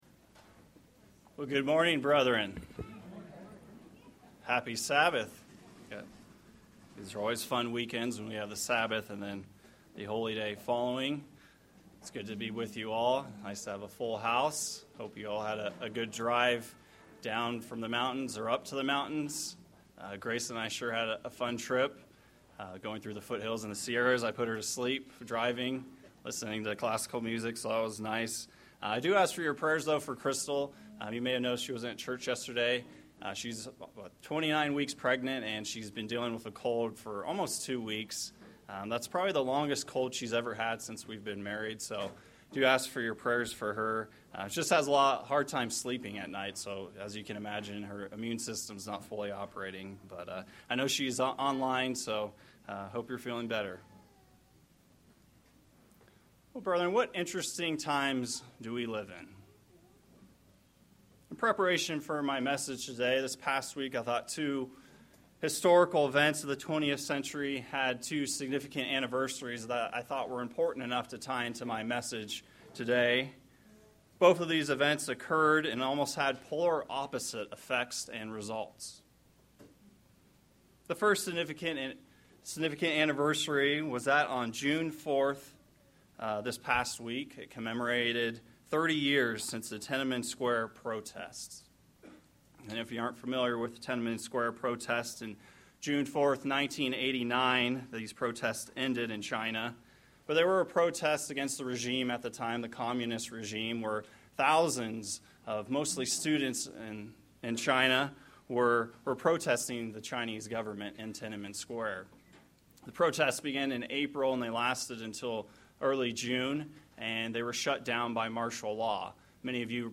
This Pentecost message focuses on the giving of Holy Spirit on the day of Pentecost, and the bearing of the fruits of this spirit by the disciple Stephen.